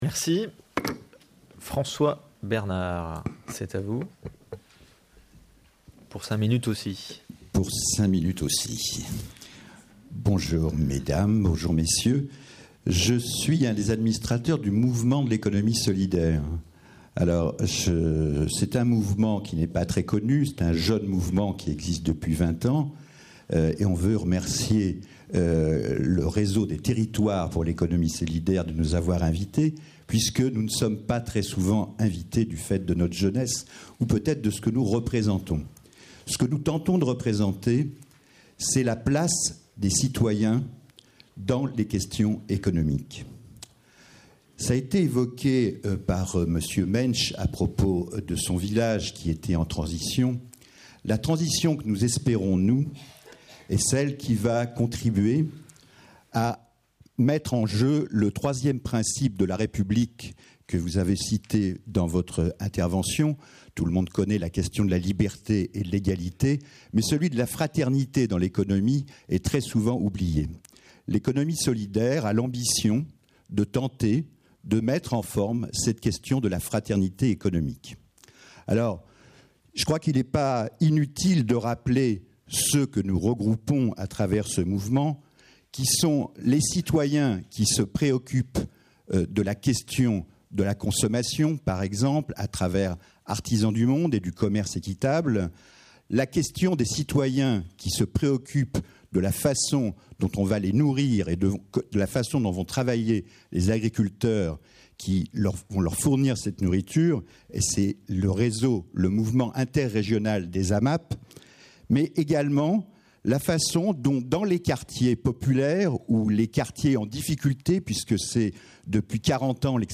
En attendant les rencontres inter-territoriales du 12 septembre, plongez ou replongez-vous dans les débats de la journée nationale autour de l'ESS, organisée le 26 juin dernier à l'Assemblée nationale.